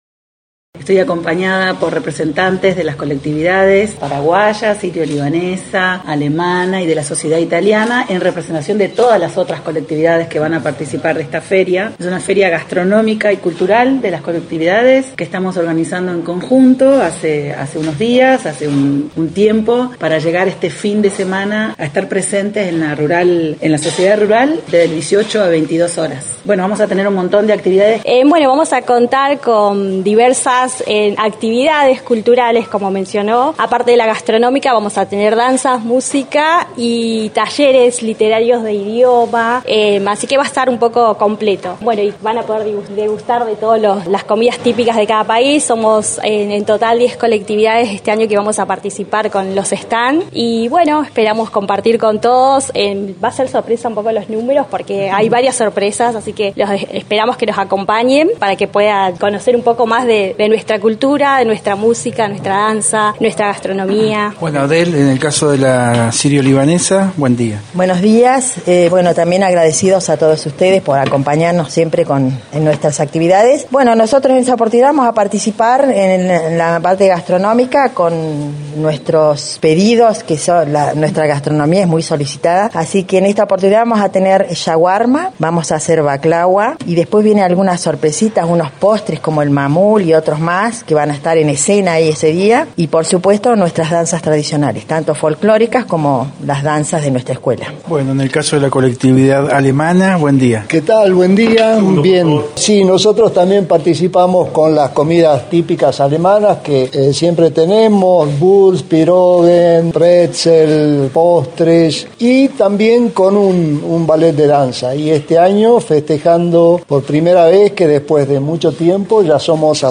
Organizado por la unión de colectividades, se anunció que este sábado de 18 a 22 hs en la Sociedad Rural, se hará una nueva edición de la feria de las colectividades. En conferencia de prensa con la participación de Sonia Baliente como Subsecretaria de Cultura, junto a integrantes de la las colectividades, Sirio Libanesa, Paraguaya, Alemana e Italiana, se invita a participar a esta feria con actividad gastronómica y cultural.